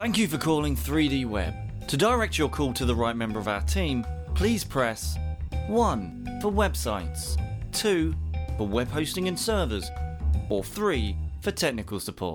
Male
Professional recording studio, Rode NT1A, Reaper, Izotope RX & more.
Phone Greetings / On Hold
Clear And Friendly Rp